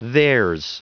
Prononciation du mot theirs en anglais (fichier audio)
Prononciation du mot : theirs